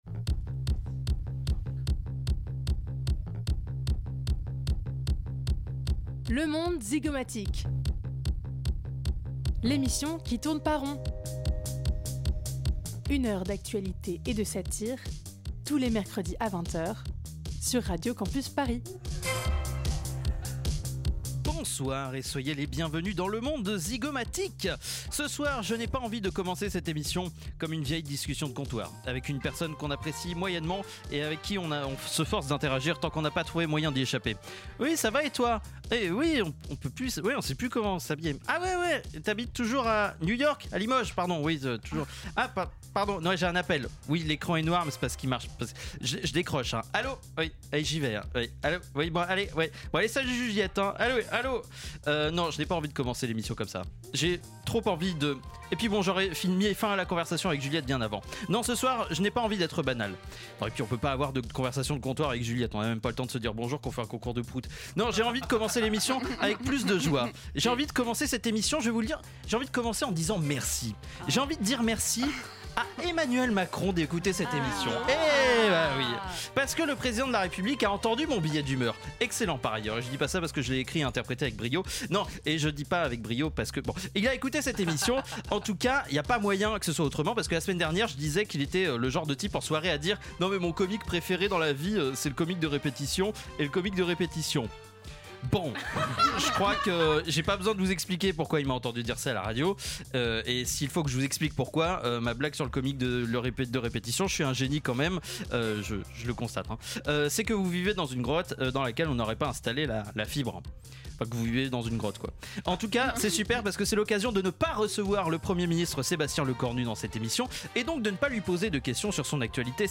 Type Magazine Société